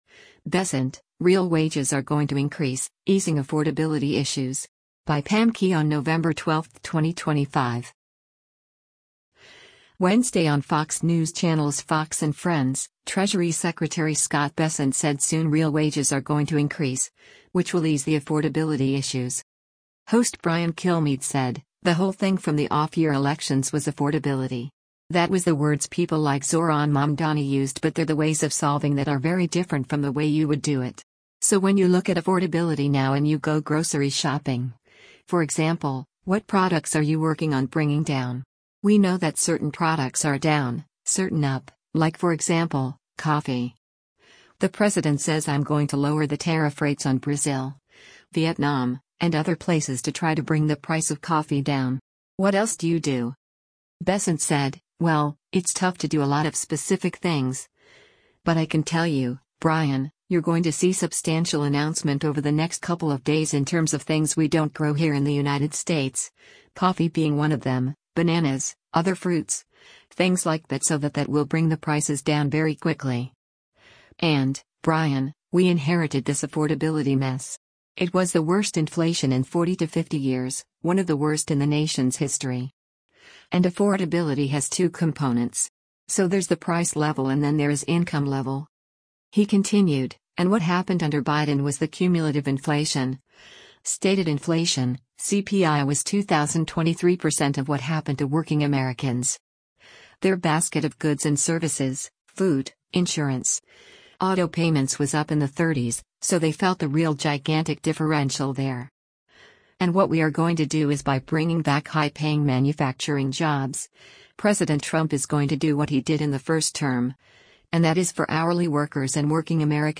Wednesday on Fox News Channel’s “Fox & Friends,” Treasury Secretary Scott Bessent said soon “real wages are going to increase,” which will ease the affordability issues.